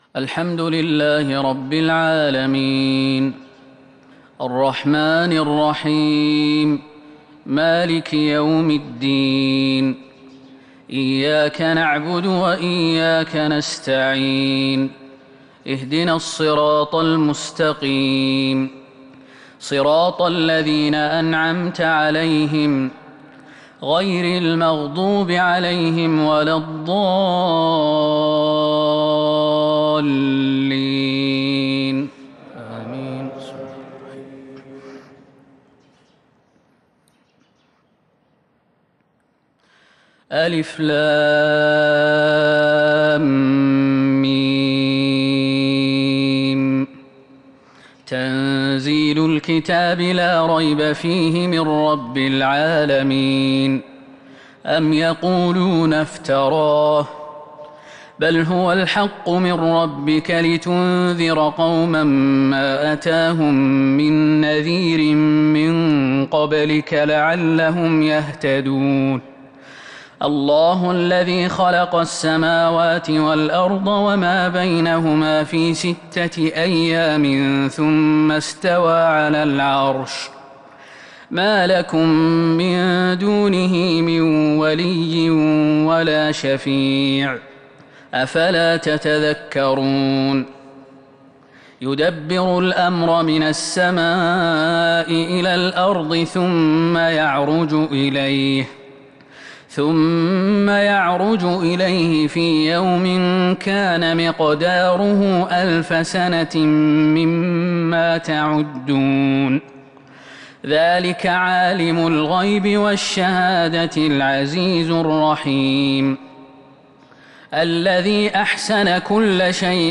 صلاة الفجر 1-2-1442 هـ سورتي السجدة و الإنسان | Fajr prayer Surah As-Sajda and Al-Insaan 18/9/2020 > 1442 🕌 > الفروض - تلاوات الحرمين